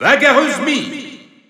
Announcer pronouncing Mii Brawler in French PAL.
Mii_Brawler_French_Announcer_SSBU.wav